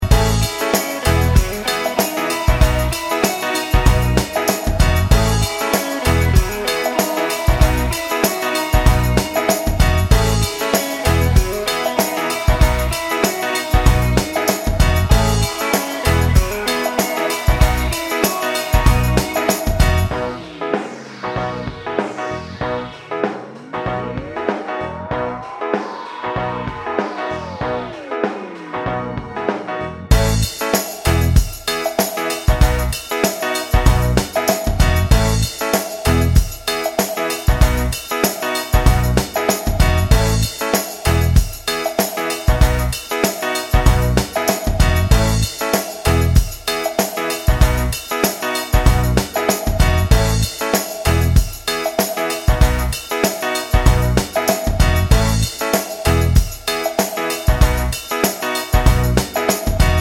For Solo Singer Pop (1990s) 3:28 Buy £1.50